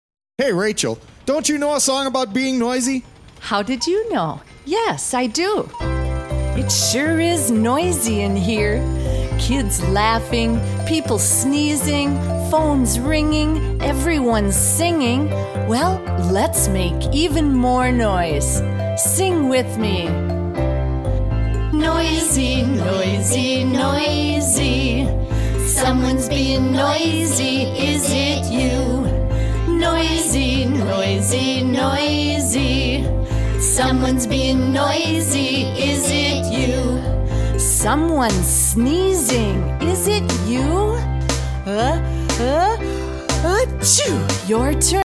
-Kids and adults singing together and taking verbal turns
Making noises